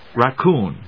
/rækúːn(米国英語), ɹəˈkuːn(英国英語)/